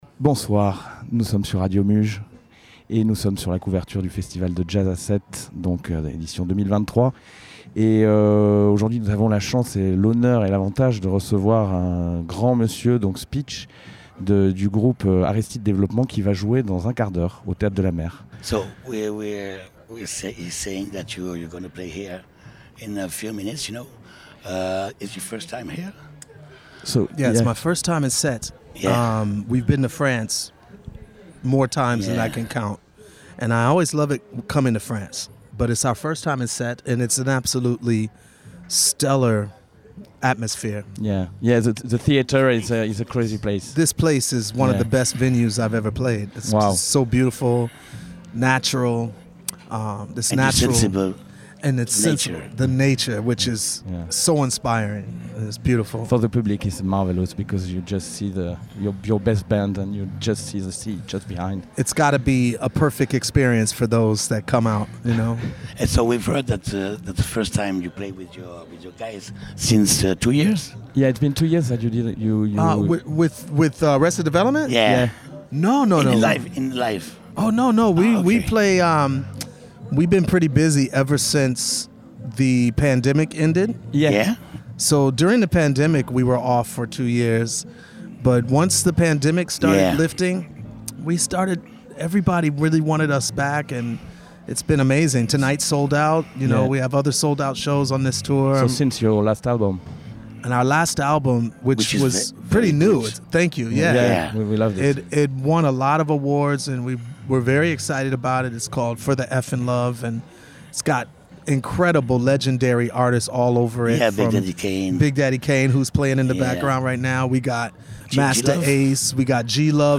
ITW de Speech, leader du groupe ARRESTED DEVELOPMENT réalisée au Théâtre de la Mer pendant le Festival Jazz à Sète le Lundi 17 Juillet, 15 minutes avant de monter sur scène...